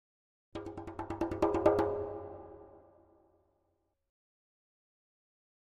Drums Percussion Danger - Slower Drumming On A Thin Metal Percussion 2